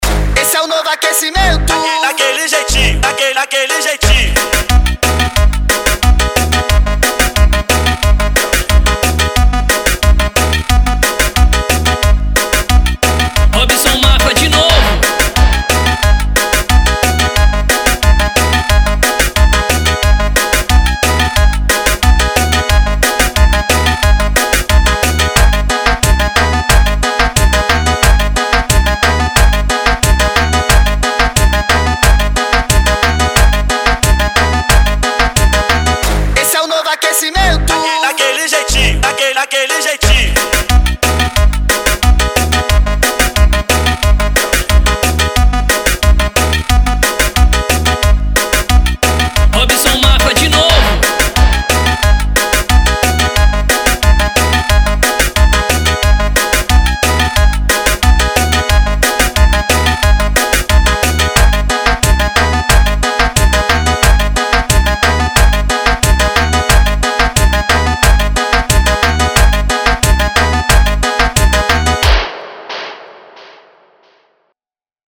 OUÇA NO YOUTUBE Labels: Tecnofunk Facebook Twitter